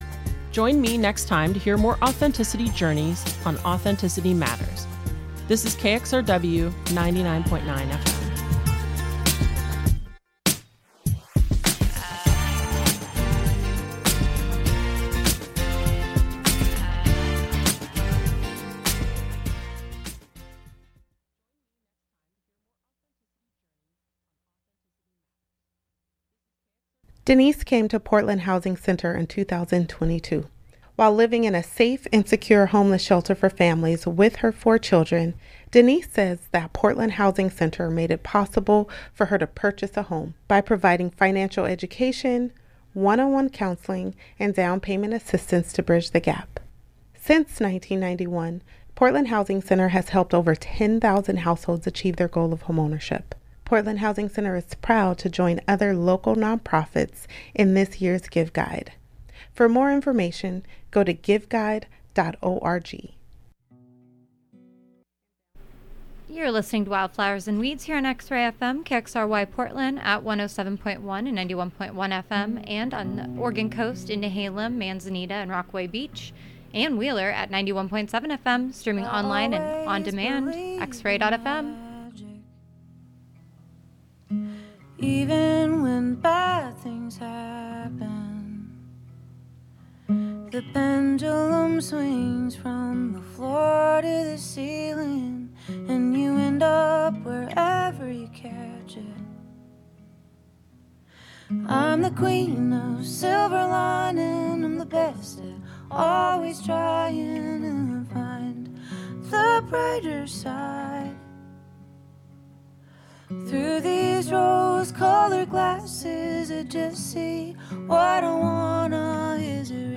A mix of independent & vintage alt, outlaw, cosmic, gothic, red dirt & honky tonk country, roots, blue grass, folk, americana, rock and roll & even desert psych and stadium hits (when acceptable).
We tie together new independent artists and new releases with classics that inspired. And we get in the weeds with artist interviews & field recordings, new releases & story telling—on every Thursday 2-3pm.